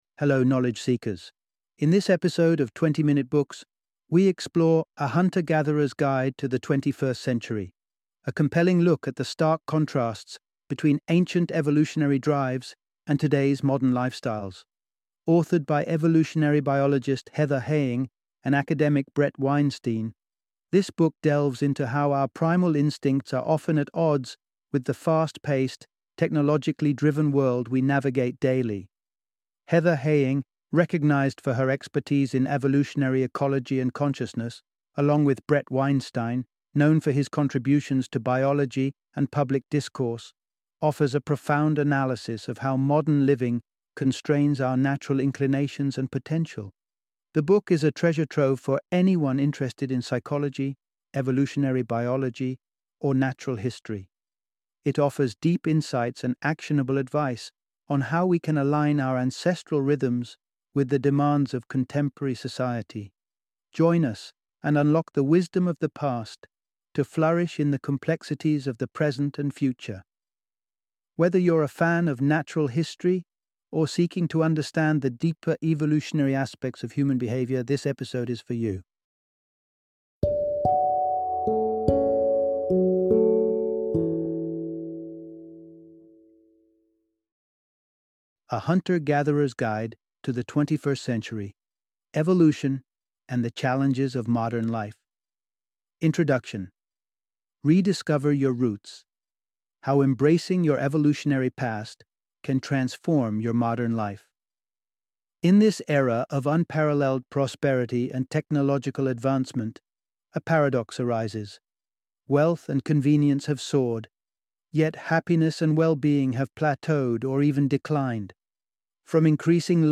A Hunter-Gatherer's Guide to the 21st Century - Audiobook Summary